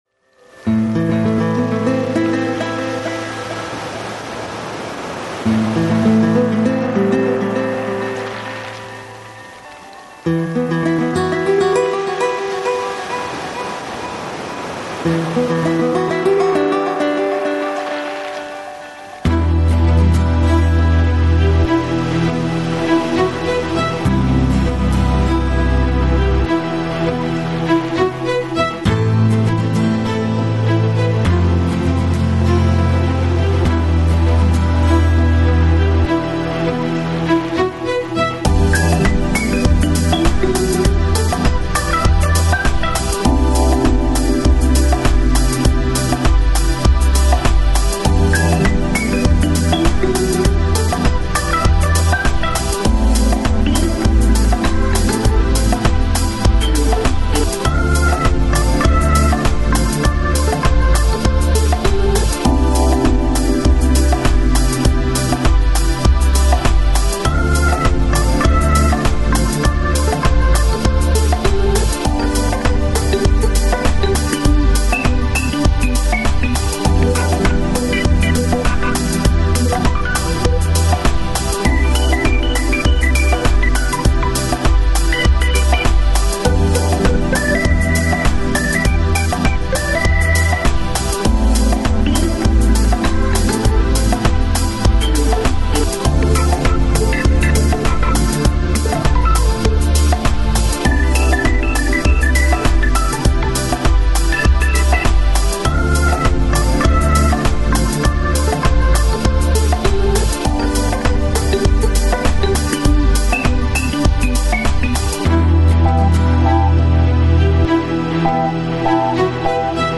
Genre: Chillout, Ambient, New Age
Quality: MP3 / Stereo